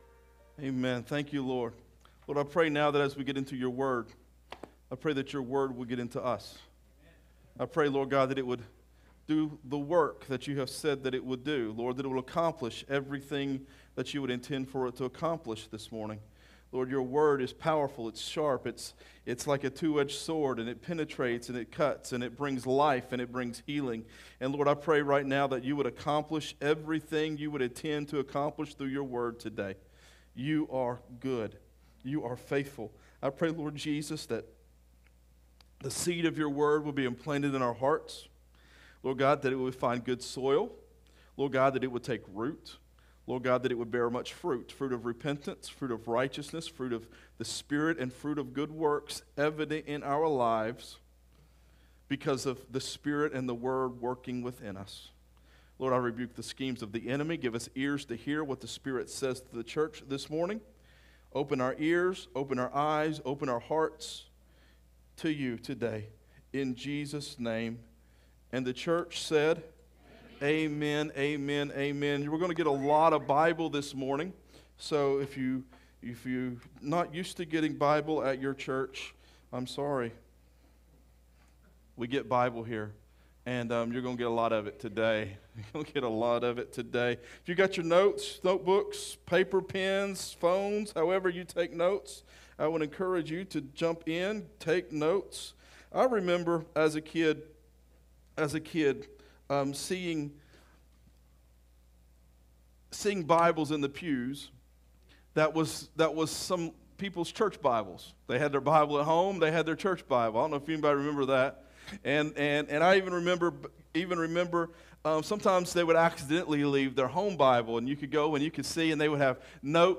Sermons | Real Life Community Church